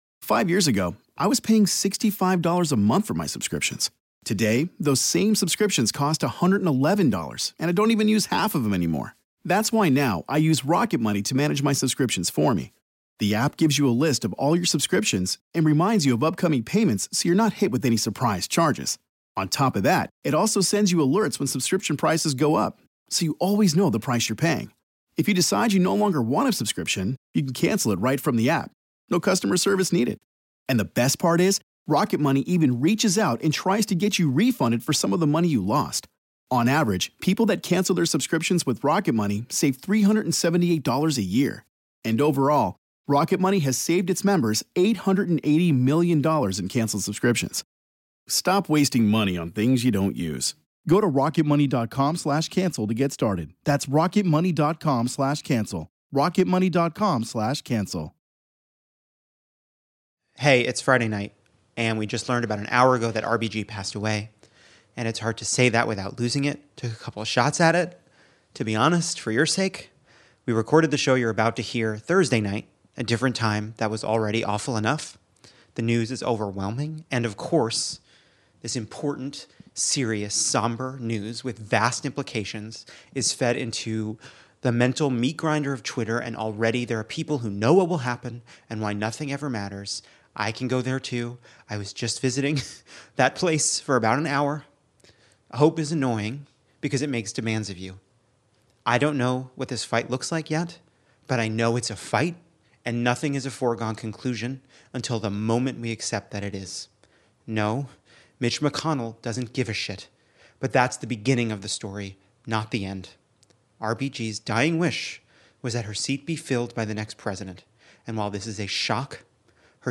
Devastating news about RBG on Friday night kicks of a fight over the future of the Supreme Court. Then we go to a pre-recorded show with guests Hari Kondabolu, Varshini Prakash from the Sunrise Movement, and a legend: Jane Fonda.